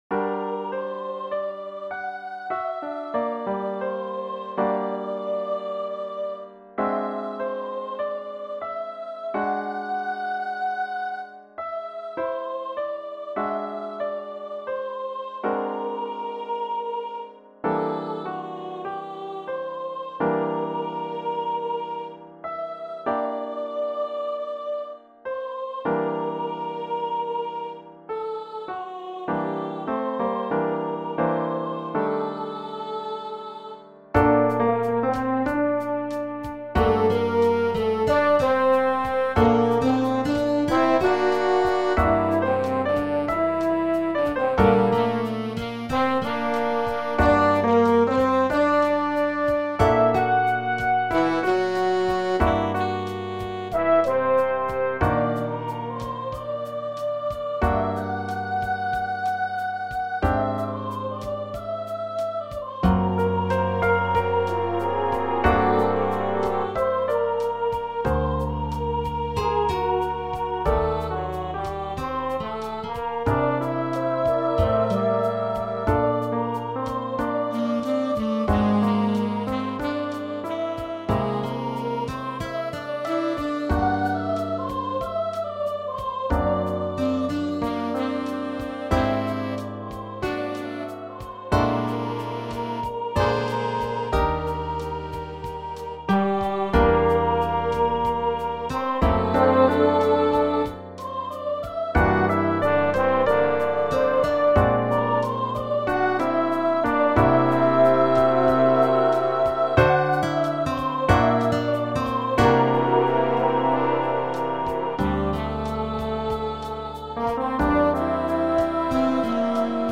jazz composition
mp3 created from the score